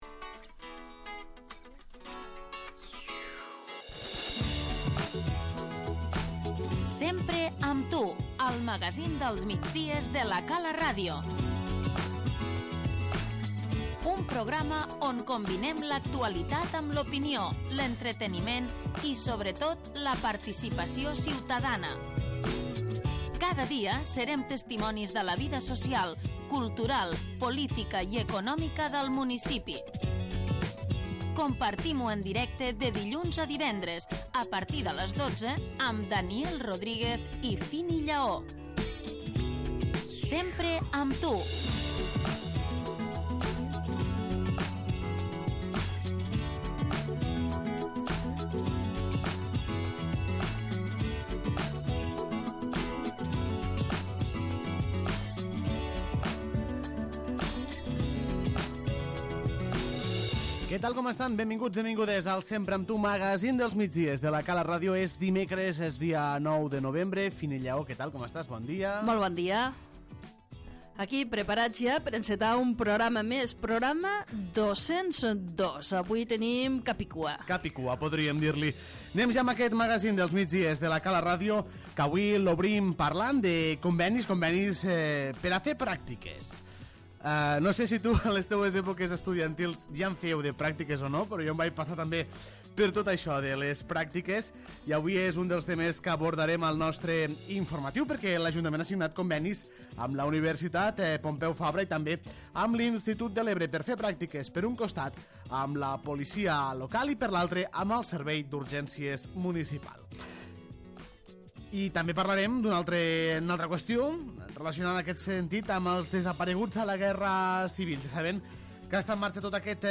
A meitat de la setmana, nova cita amb el Sempre amb tu, el magazín dels migdies de La Cala Ràdio.